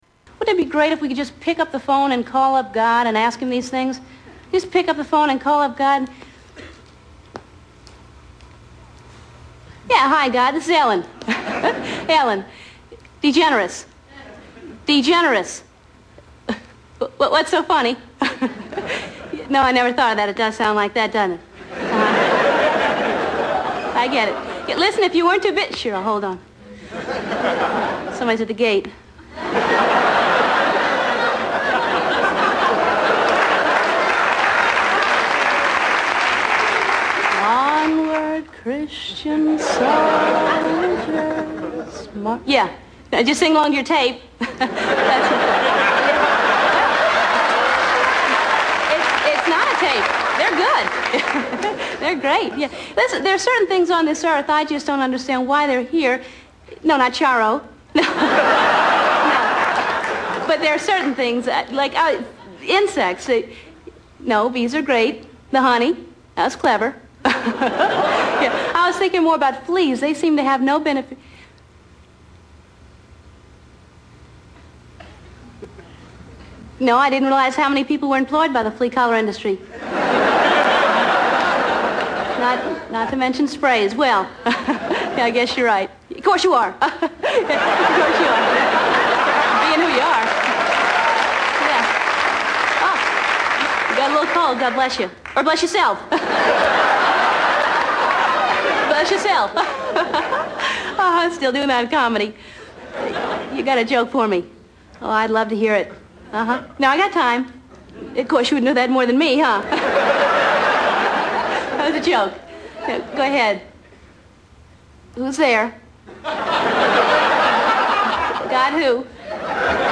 Category: Comedians   Right: Personal
Tags: Comedian Celebrity Ellen DeGeneres TEDS Funny